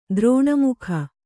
♪ drōṇa mukha